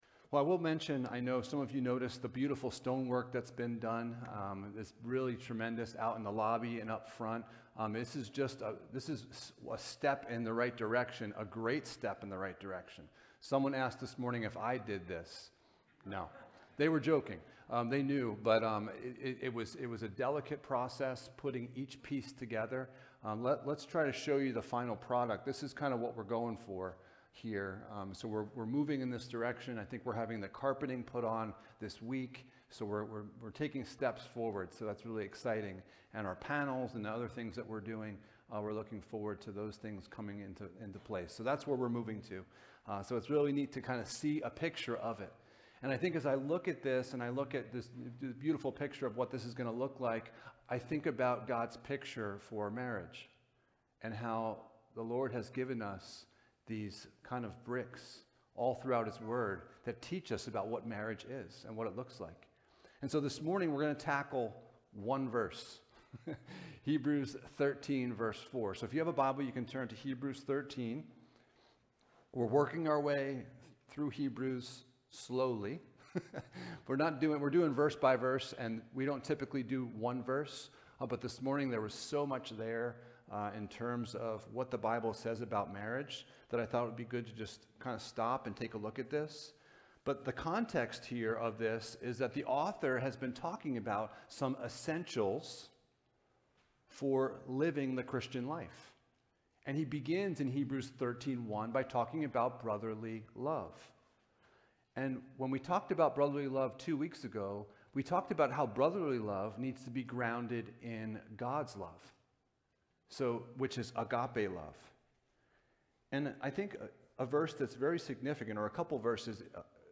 Hebrews 13:4 Service Type: Sunday Morning « Finding Biblical Clarity in a World of Cultural Confusion What Happened to God’s Design for Church Leadership?